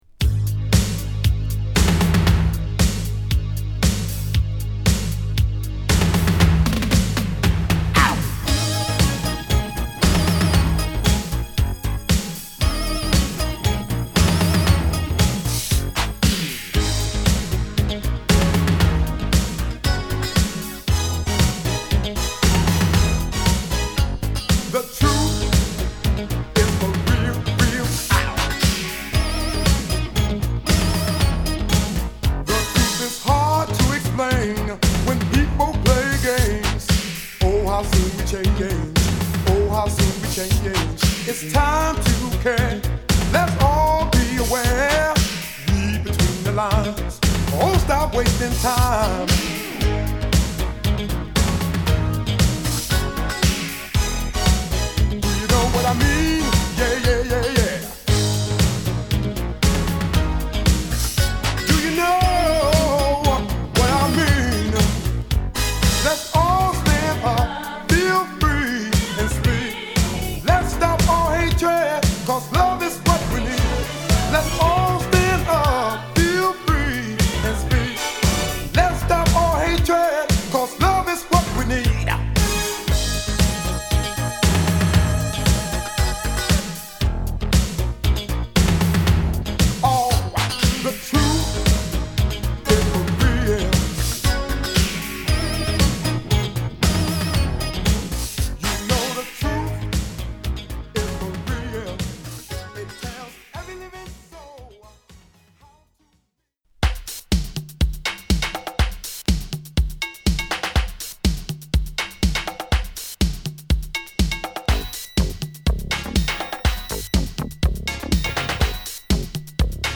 ハウス・ミュージックのシンガーとして